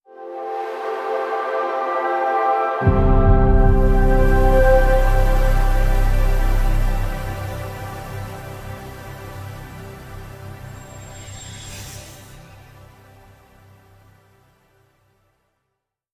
XBOX Series X Startup.wav